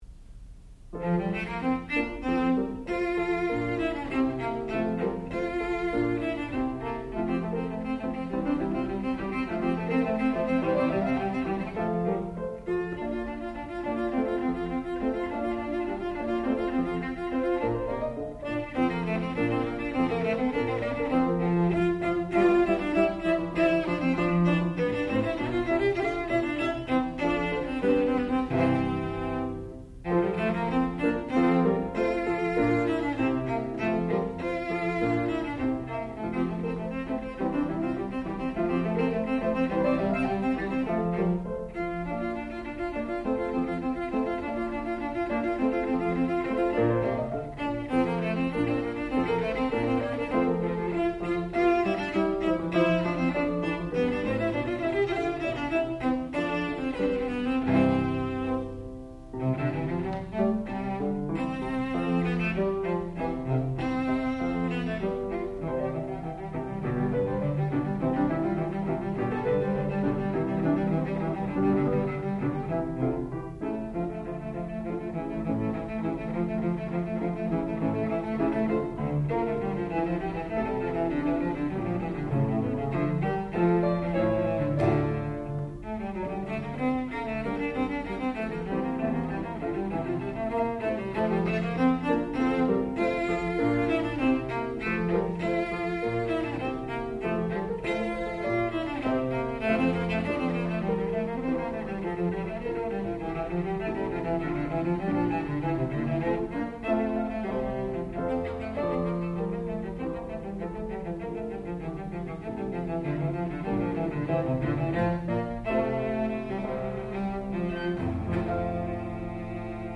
sound recording-musical
classical music
violoncello
piano